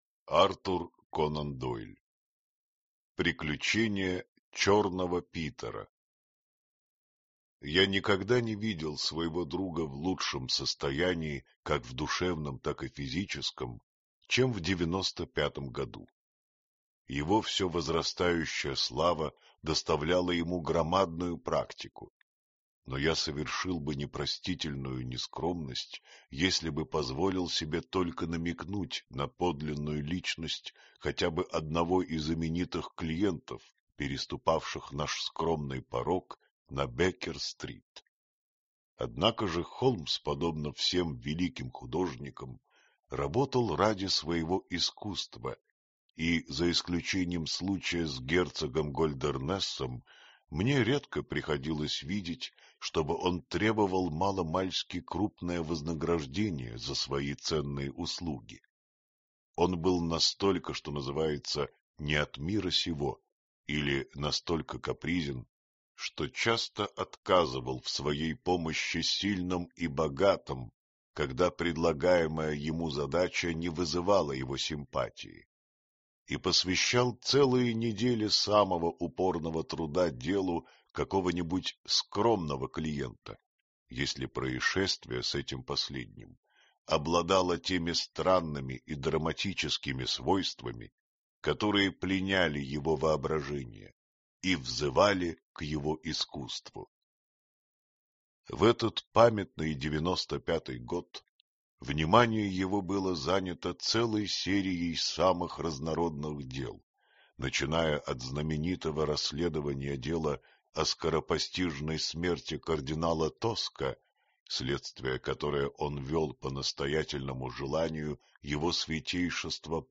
Аудиокнига Приключения Шерлока Холмса. Рассказы | Библиотека аудиокниг